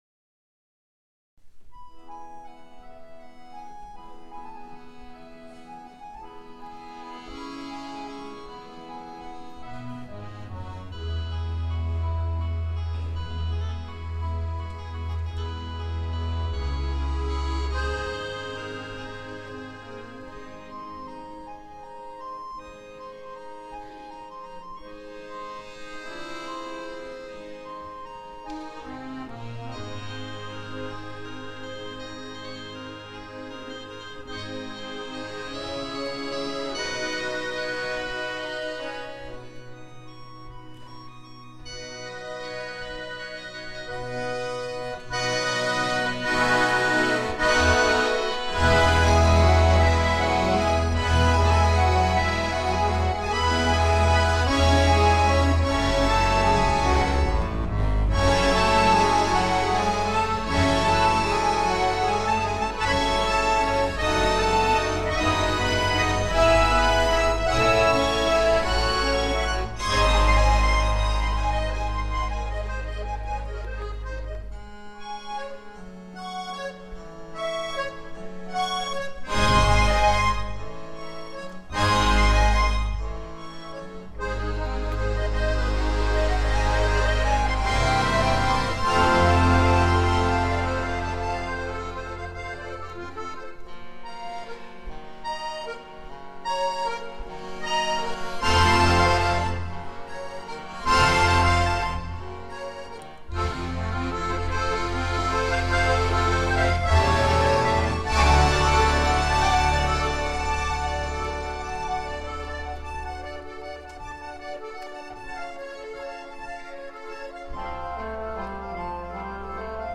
2014 – Akkordeonorchester Neustadt bei Coburg e. V.